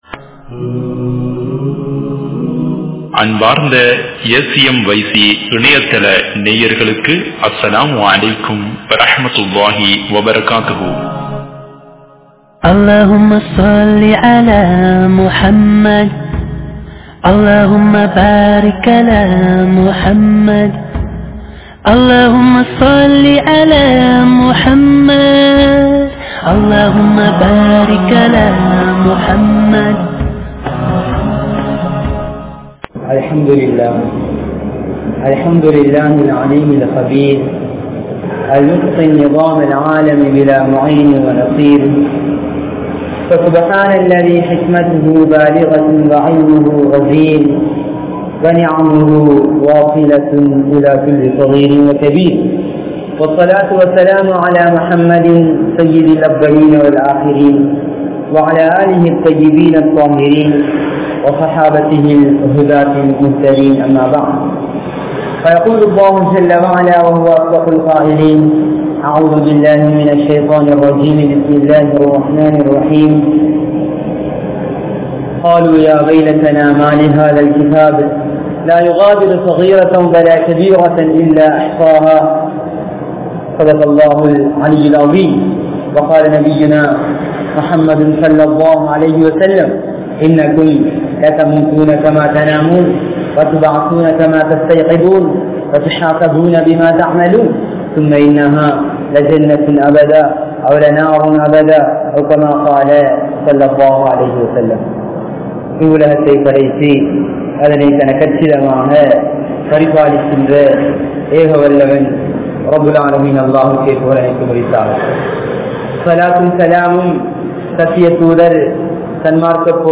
Paavaththin Vilaivuhal (பாவத்தின் விளைவுகள்) | Audio Bayans | All Ceylon Muslim Youth Community | Addalaichenai